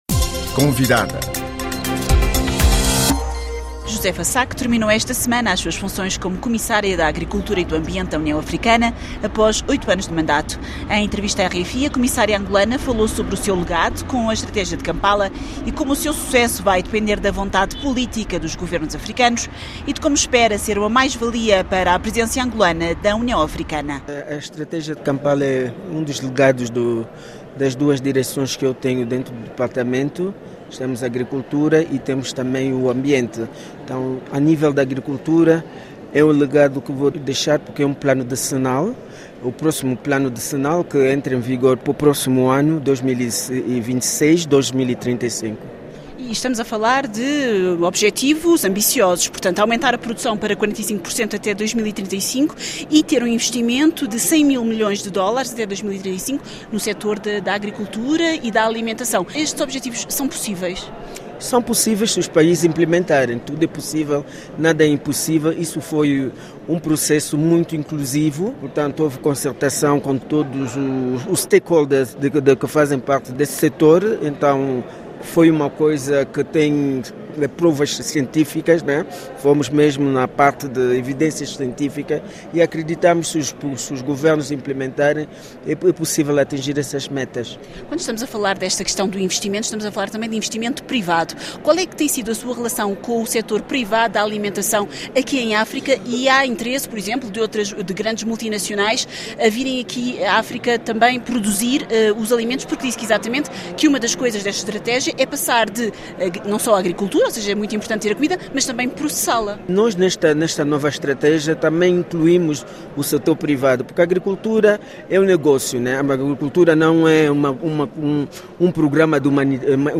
Ao seu lado e para o aconselhar, terá Josefa Sacko, até agora comissária da Agricultura e do Ambiente da União Africana que terminou o seu mandato de oito anos. Em entrevista à RFI, a comissária angolana diz-se satisfeita do seu legado e tem o sentimento de dever cumprido.